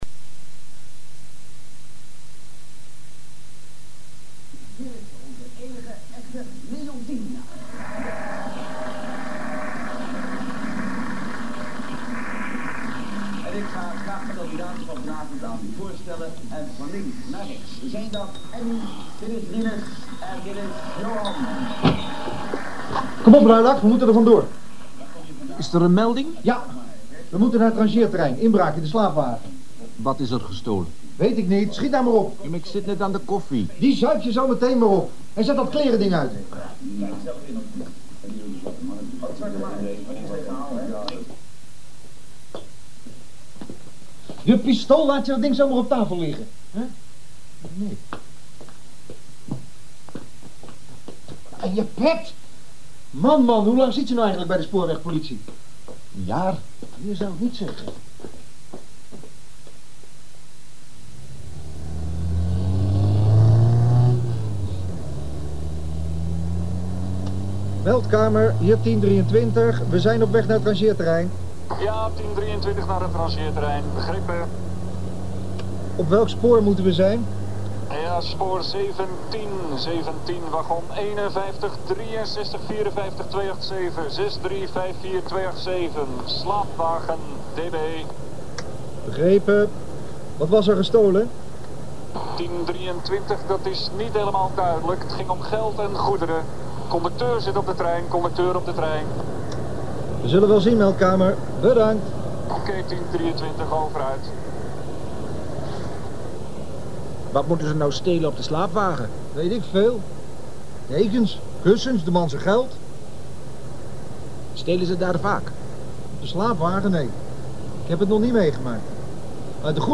Drama (KRO) 1 deel Verhaal: Twee agenten van de Spoorwegpolitie krijgen van de meldkamer te horen dat er is ingebroken in een wagon op het rangeerterrein.